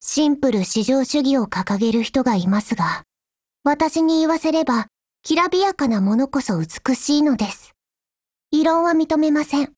贡献 ） 协议：Copyright，其他分类： 分类:少女前线:史蒂文斯520 、 分类:语音 您不可以覆盖此文件。
Stevens520_DIALOGUE3_JP.wav